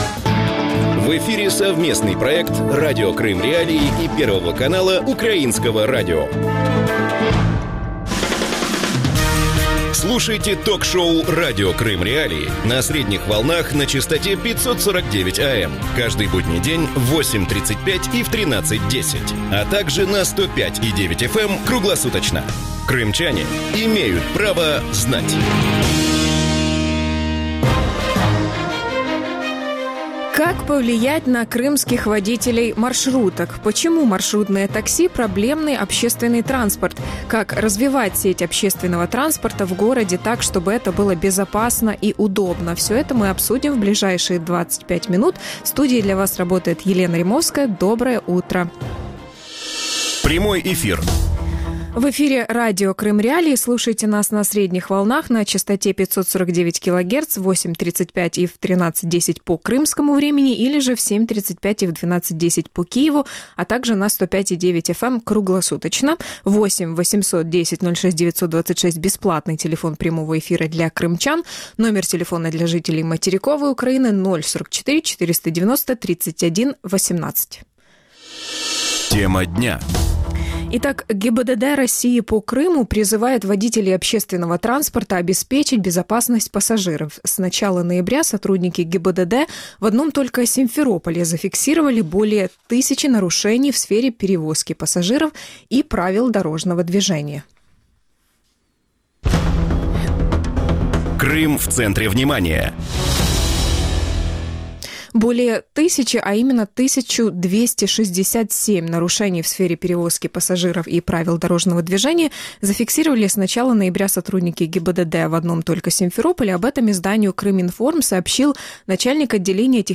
Почему маршрутные такси - проблемный общественный транспорт? Как развивать сеть общественного транспорта в городе так, чтобы это было безопасно и удобно? Гости эфира